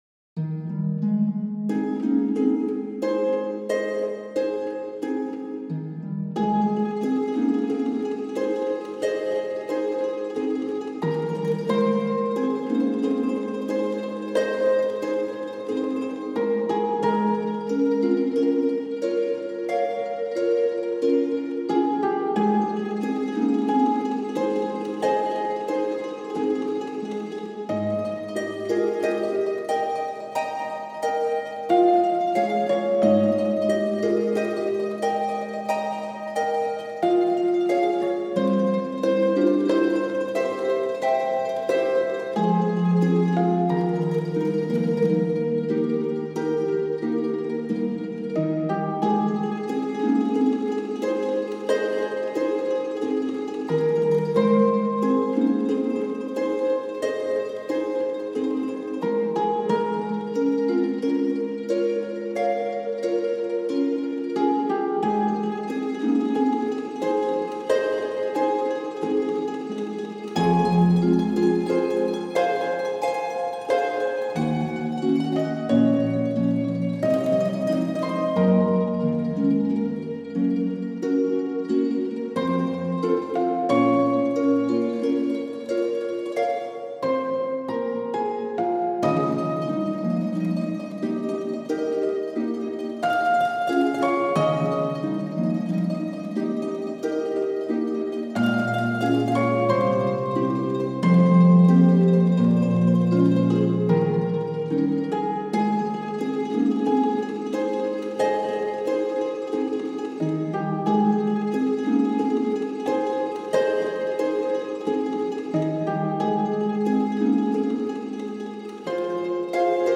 for four pedal harps